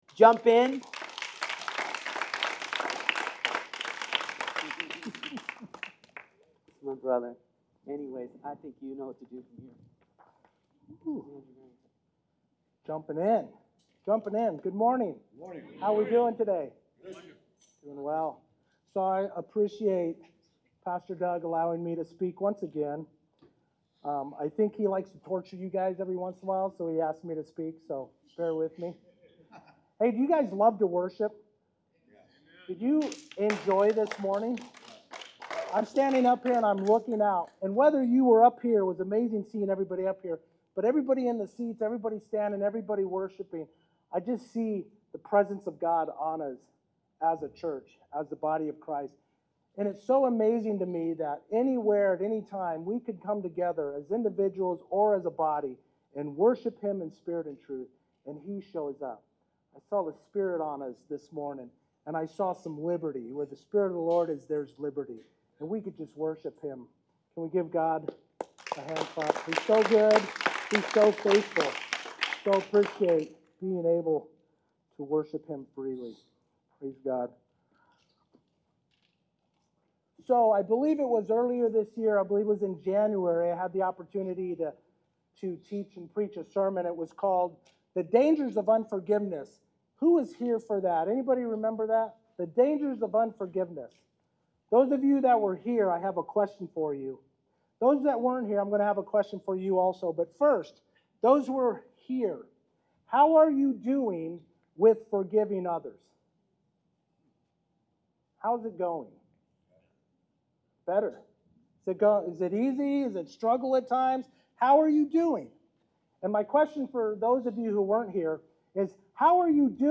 Sermon Series: God Meant It for Good — The Journey of Joseph